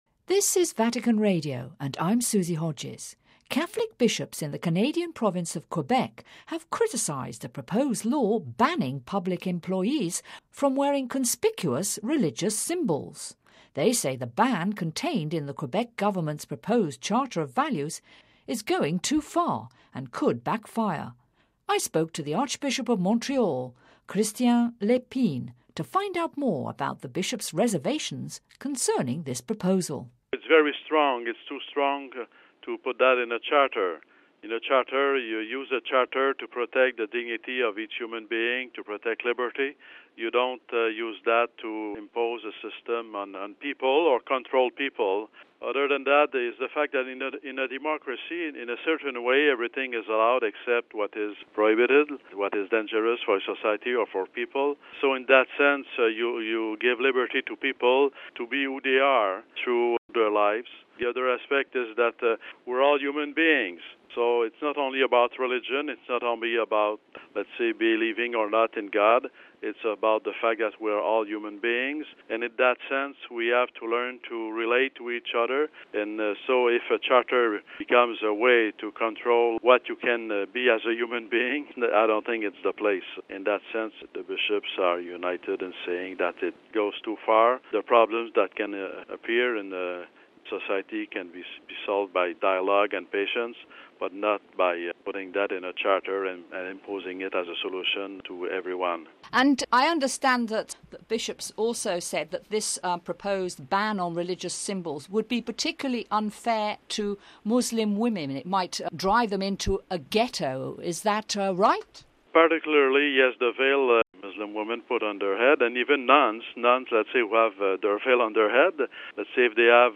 Listen to the full interview with Archbishop Lépine: RealAudio